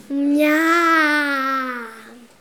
ajout des sons enregistrés à l'afk
miam_01.wav